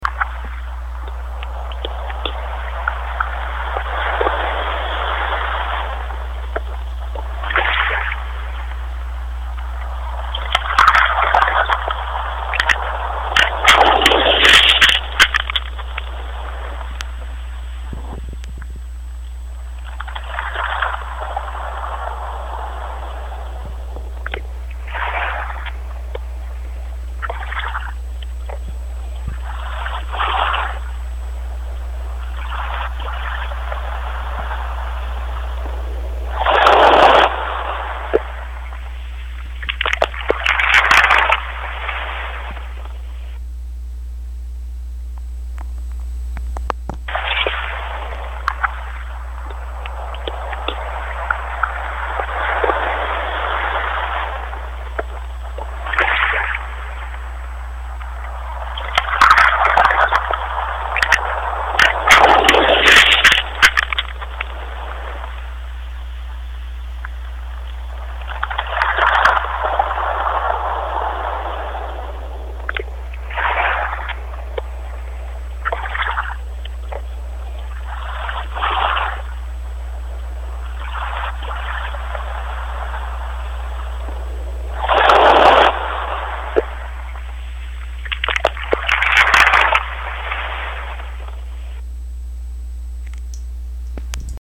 휴대폰으로 몽돌 해수욕장 바도 소리 자갈 구르는 소리도 녹음도 하고....
파도소리,
자갈 구르는 소리